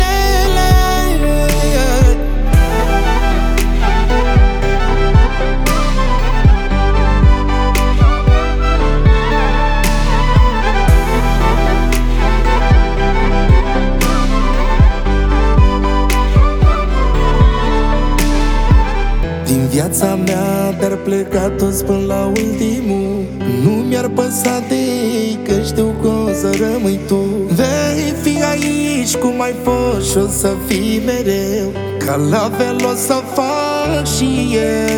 Жанр: Музыка мира